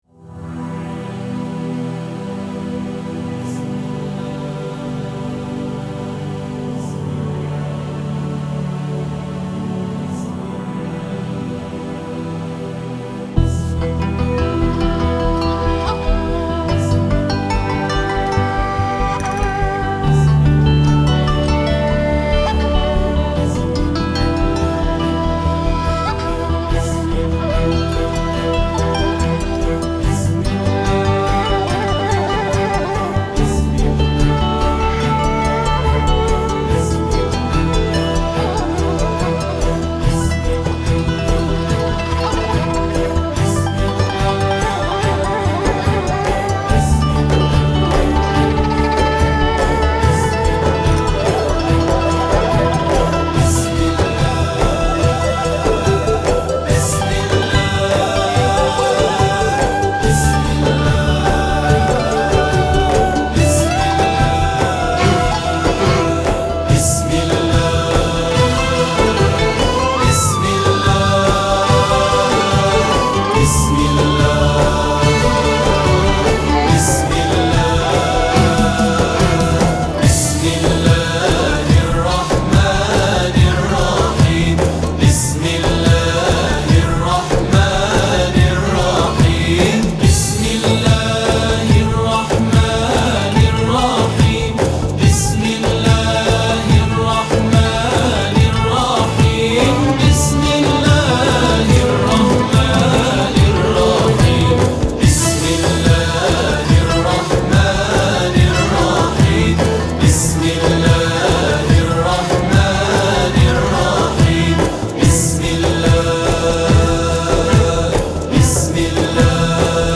نشيد